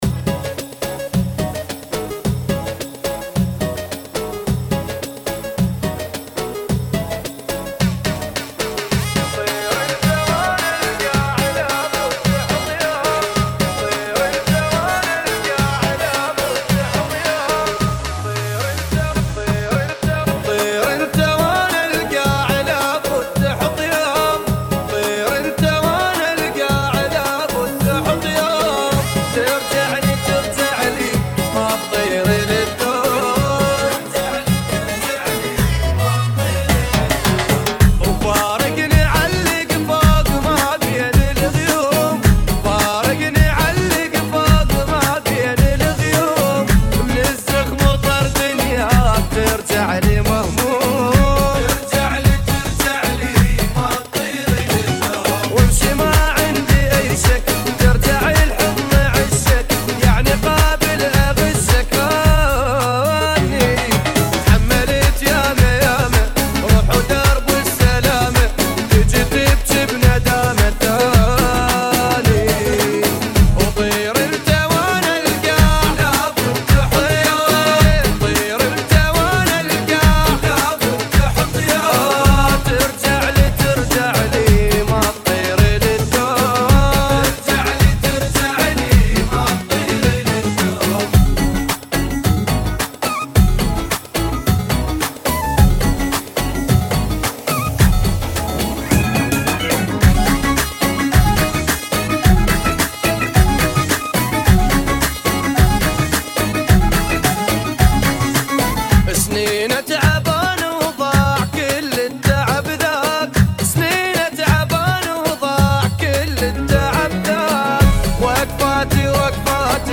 108 Bpm